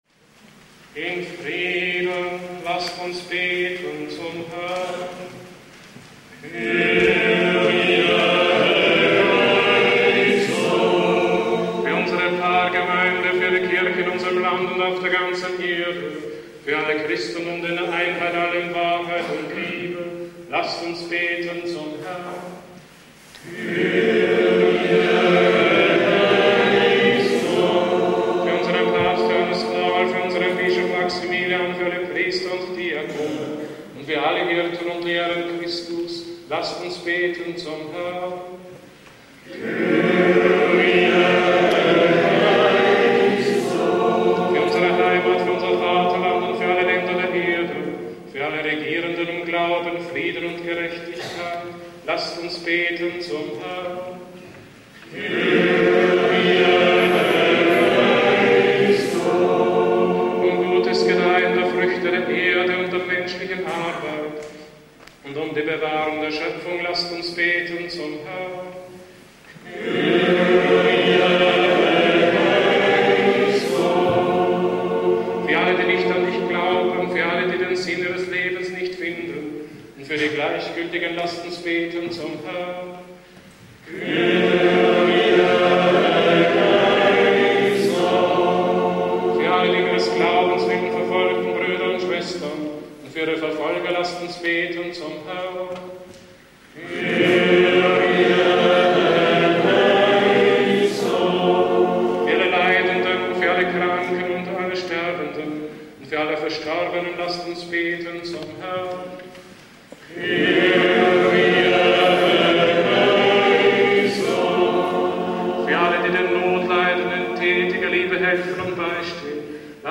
Für den Gebetsrahmen wählte der Gründer wegen seiner Ausdrucksstärke und seiner Erlernbarkeit den Kiewer Choral, wie er der liturgischen Praxis im bayerischen Kloster Niederaltaich (byzantinische Dekanie) zu Grunde liegt, hier mit strenger Terzparallele und Funktionsbass.